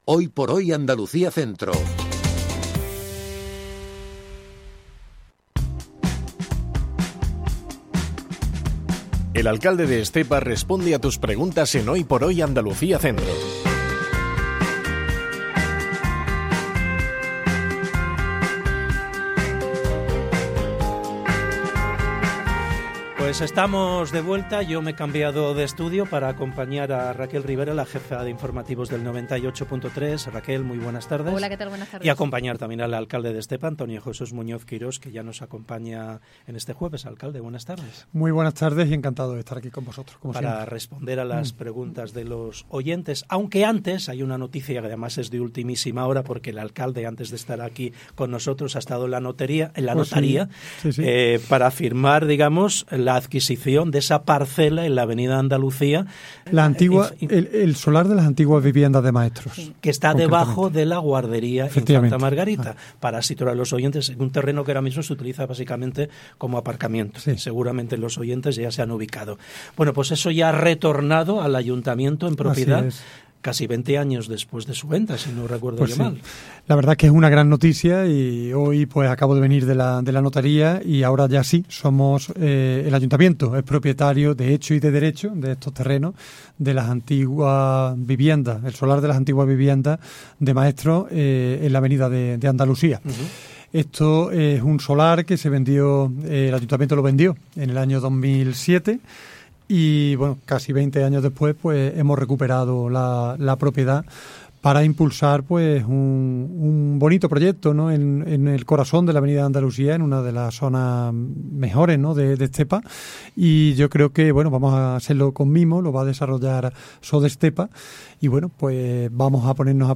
EL ALCALDE RESPONDE 26 MARZO 2026 Antonio Jesús Muñoz Quirós, alcalde de Estepa, responde a las preguntas de los oyentes en Hoy por Hoy Andalucía Centro.